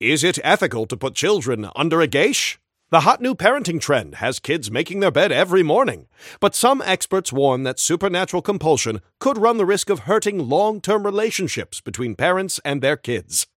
Newscaster_headline_83.mp3